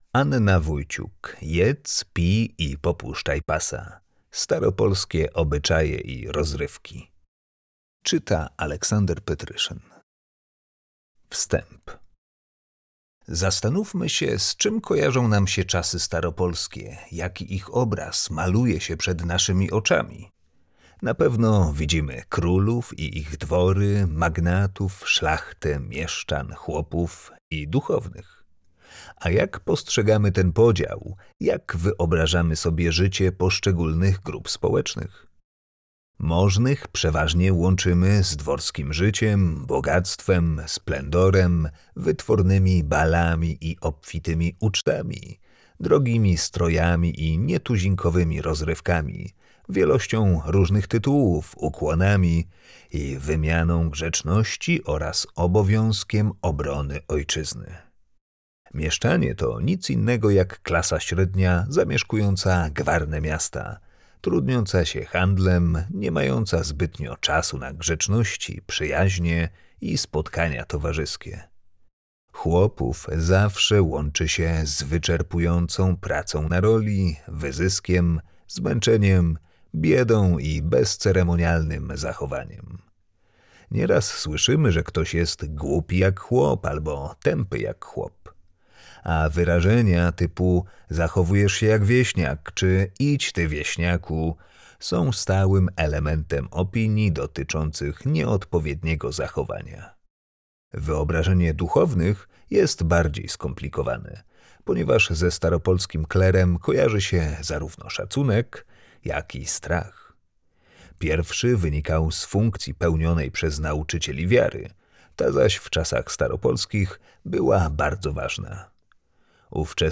Audiobook dostępny również jako e-book .